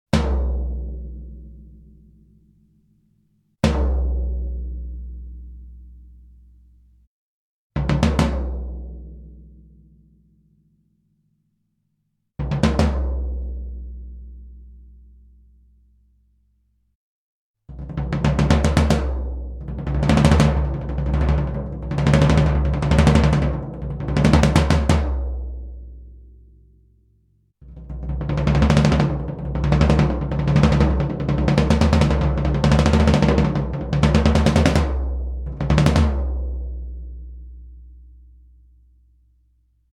Das Standtom wurde wie im folgenden Bild zu sehen aufgebaut und mikrofoniert - zum Einsatz kamen zwei AKG C414B-ULS in einer ORTF-Anordnung, die direkt über ein Tascam DM24-Pult ohne weitere Bearbeitung mit 24 Bit in Samplitude aufgenommen wurden.
Bei diesem File hört man den Unterschied übrigens sehr viel deutlicher, da die harten Attacks im Vergleich zu dem folgenden Ton etwas heruntergeregelt sind - der "Körper" der Trommel also besser in dern Vordergrund tritt. Auch hier ist immer zuerst das Vollgummi, dann die Luft-Version zu hören:
Alle Einzelaufnahmen zusammen - mit Limiter und Kompression